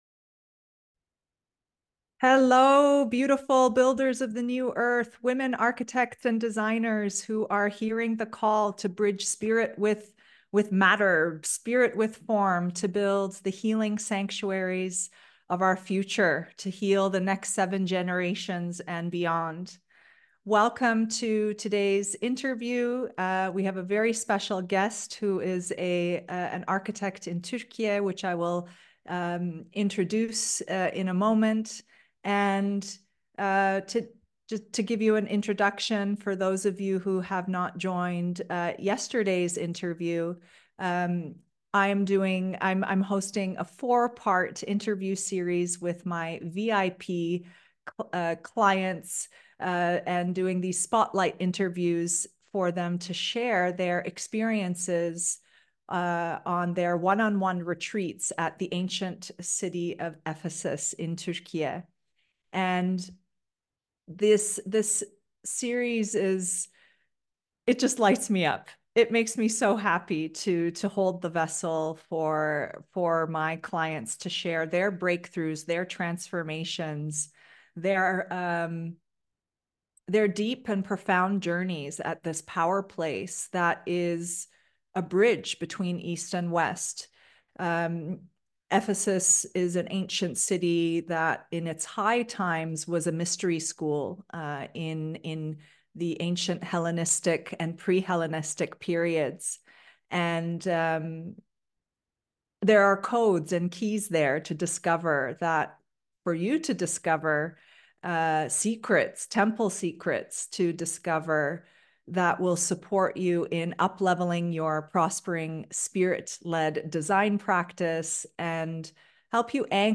Join our special 4-Part VIP Client Spotlight Interviews: Discover Ancient Temple Secrets of Ephesus where you'll hear about my incredible clients' transformative journeys to Ephesus -Mega Power Place, Mystery School Centre, Bridge Between East & West, and Home to one of the 7 Wonders of the World!